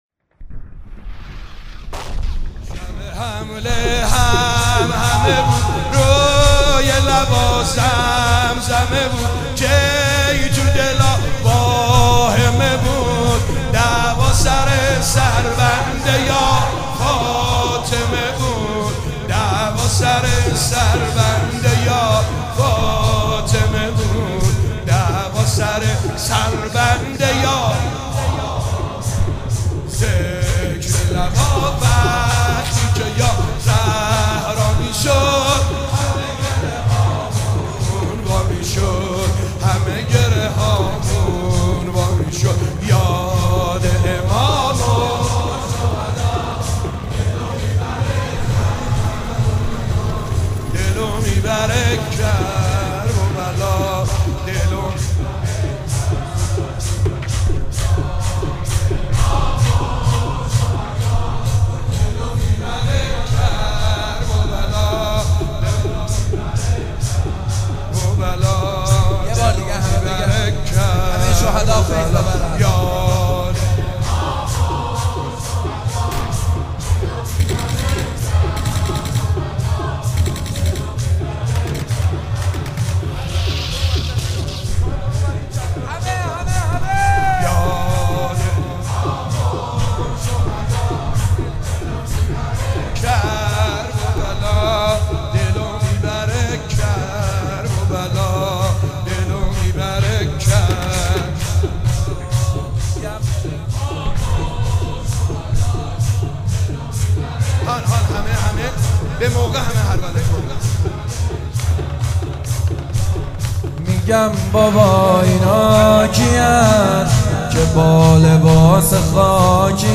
مداحی های زیبا برای وفات حضرت خدیجه کبری (سلام الله علیها)